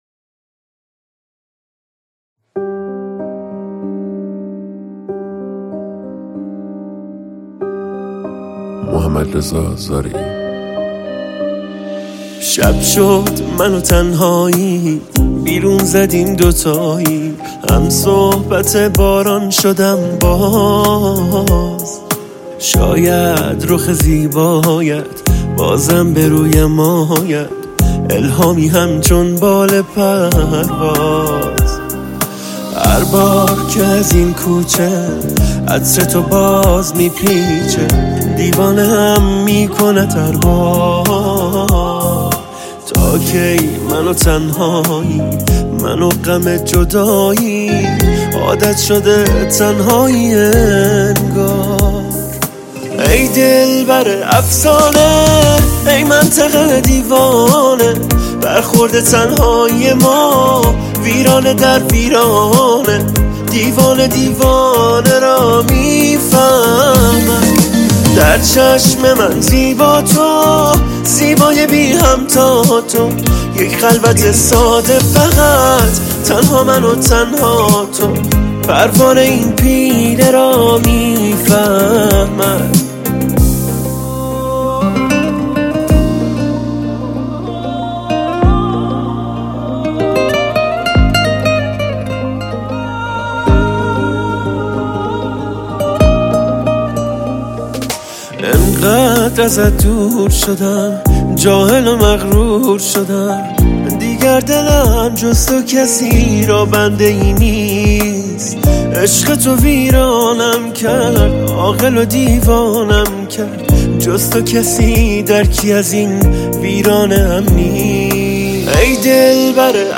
دانلود آهنگ شاد با کیفیت ۱۲۸ MP3 ۳ MB